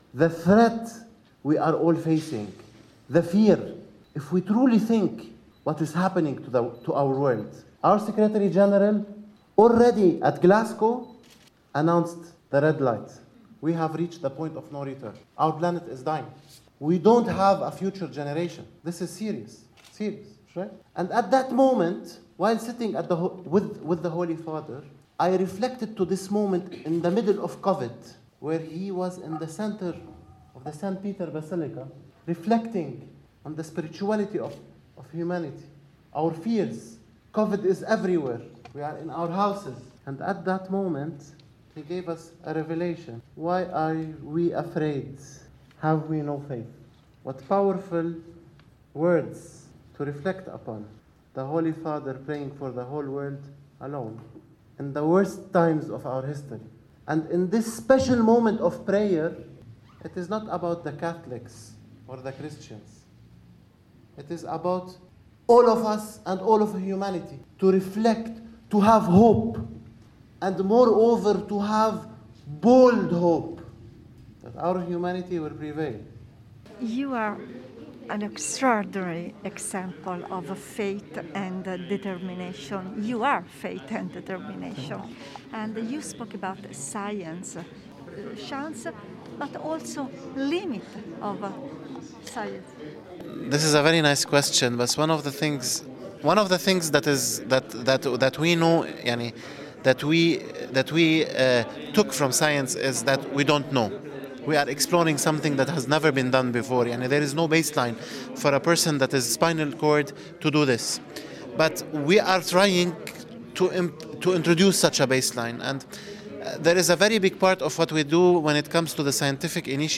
Intervista originale in inglese